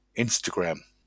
[ˈɪnstəɡɹæm](info) uff Anglisch, De-Instagram.ogg [ˈɪnstəɡʁɛm](info) odd’r [ˈɪnstaɡʁam] uff Diitsch,[3] LL-Q150 (fra)-0x010C-Instagram.wav [ɛ̃staɡʁam](info) uff Frànzeesch; umgàngsschproochlig àui IG odd’r Insta) ìsch a Soziààl Nätzwark, wu maa hàuiptsachlig Fotos un Videos tailt.